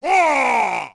Barbarian King Scream Clash Of Clans Sound Effect Free Download
Barbarian King Scream Clash Of Clans